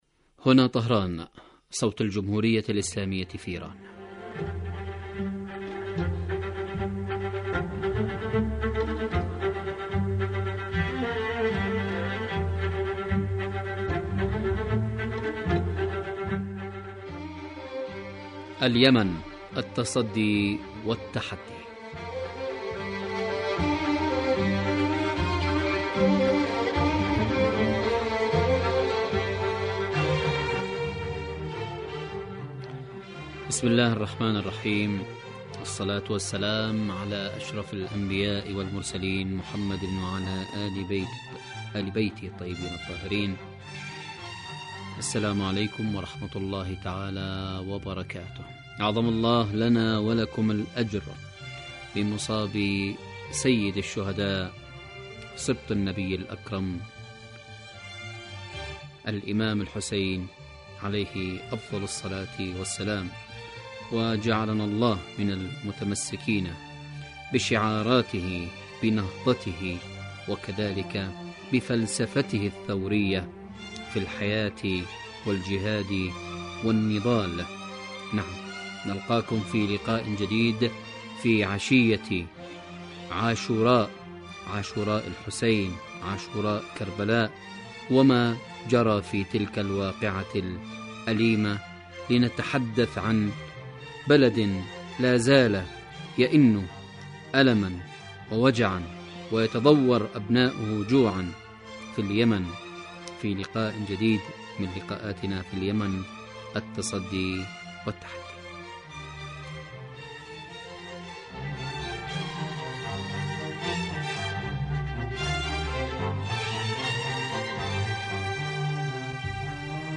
برنامج سياسي حواري يأتيكم مساء كل يوم من إذاعة طهران صوت الجمهورية الإسلامية في ايران .
البرنامج يتناول بالدراسة والتحليل آخر مستجدات العدوان السعودي الأمريكي على الشعب اليمني بحضور محللين و باحثين في الاستوديو